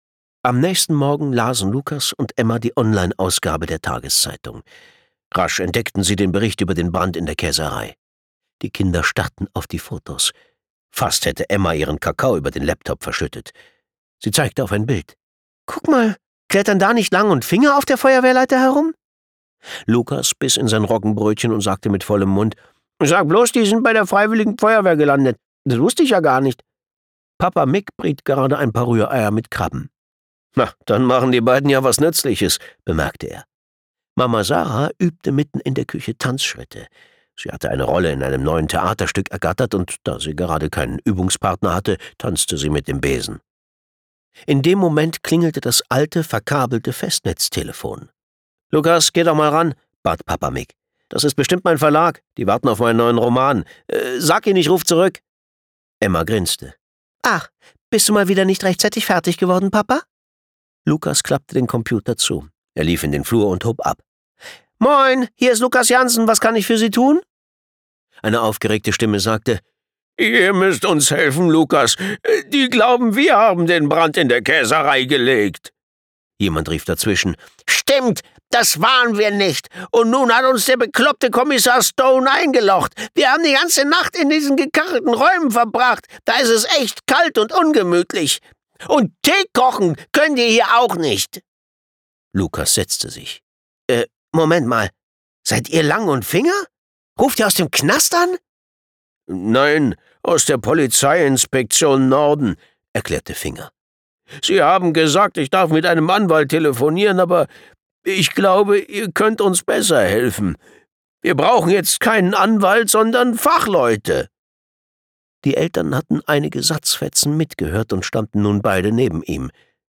Hörbuch: Die Nordseedetektive.